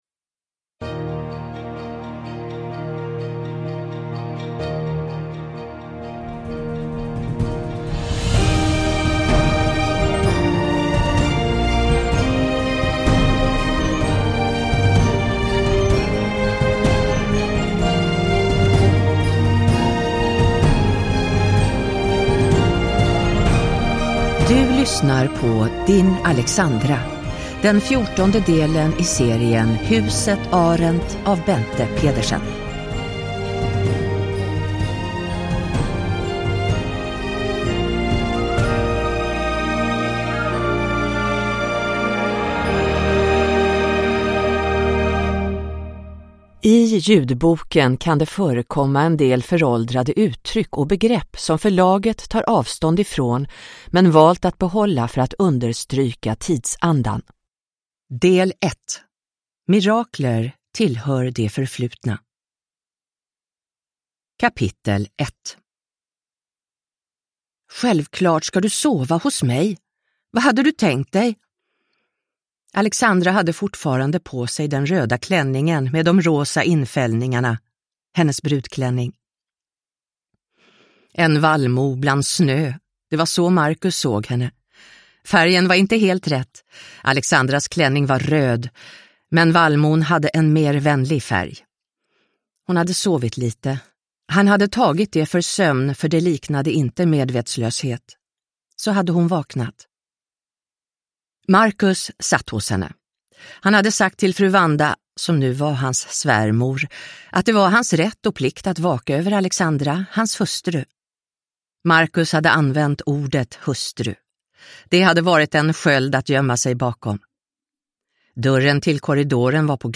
Din Aleksandra – Ljudbok – Laddas ner